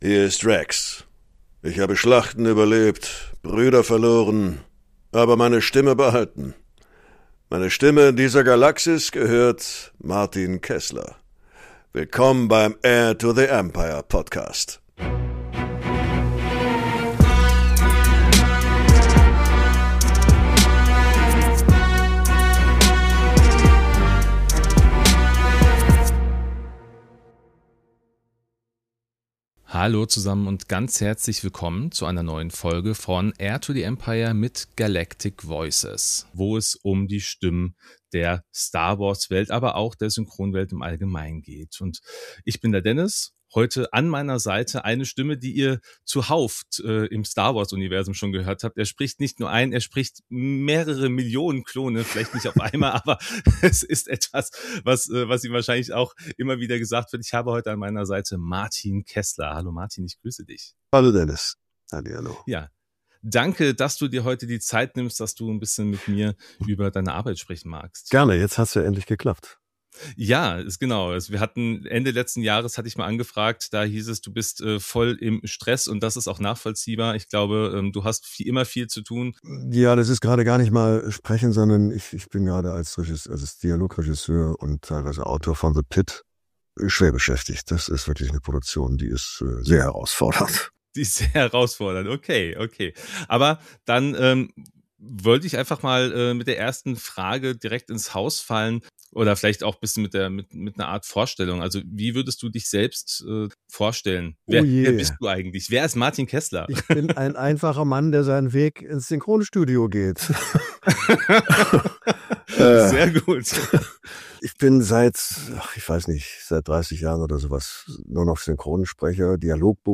Unser Gast in dieser Folge ist Martin Keßler – die deutsche Stimme aller Klone im Star-Wars-Universum. Ob Klonsoldaten der Großen Armee der Republik, Captain Rex oder Commander Cody: Mit seiner markanten, klaren und charakterstarken Stimme verleiht Martin Keßler den genetisch identischen Kriegern Individualität, Tiefe und Wiedererkennbarkeit.